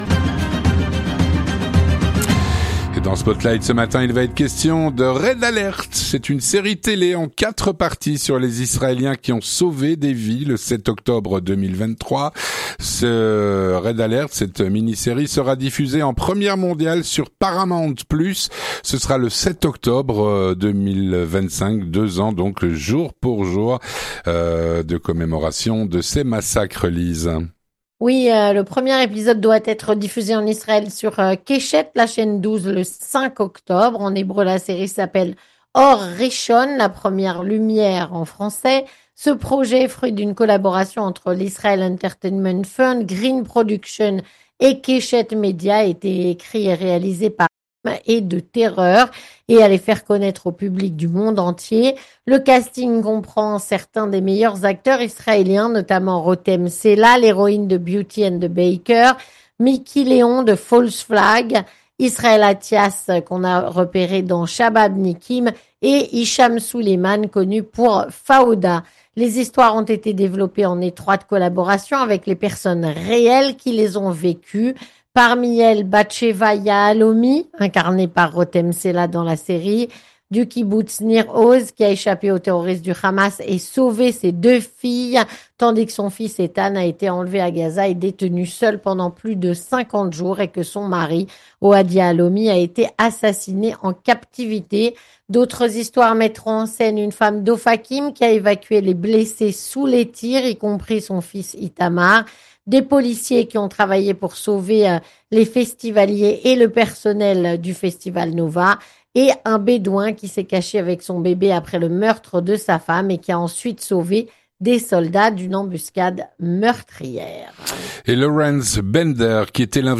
une chronique écrite et présentée